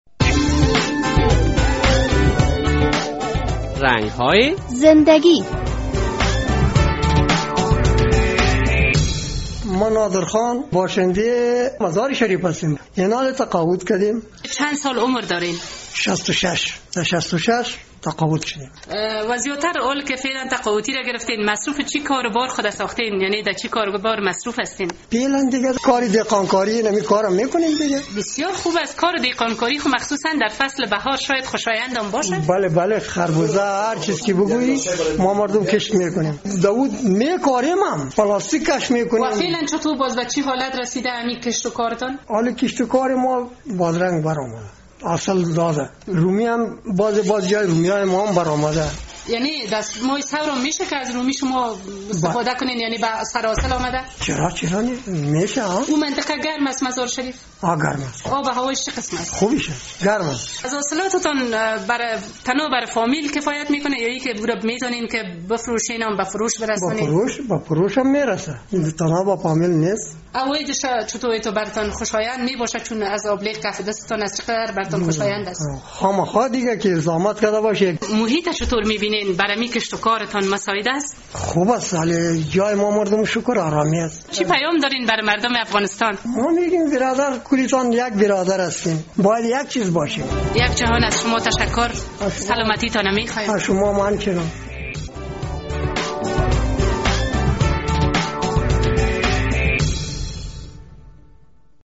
مرد کهن سال